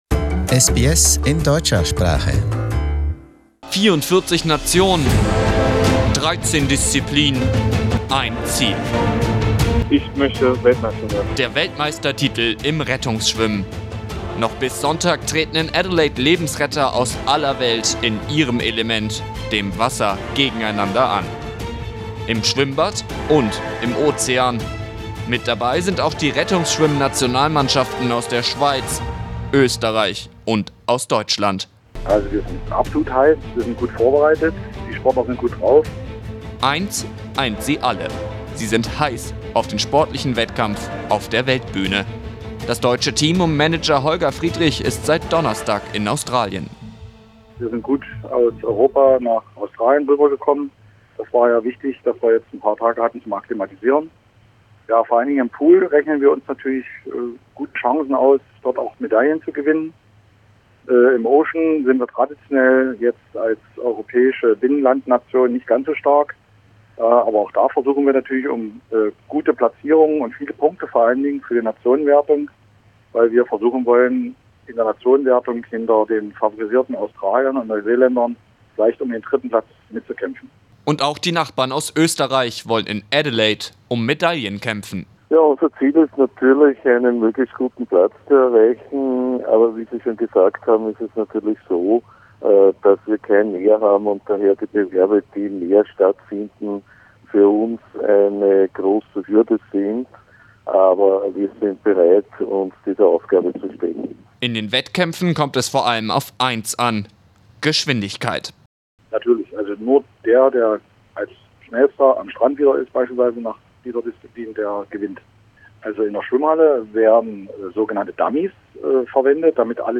We spoke to the German team to find out more.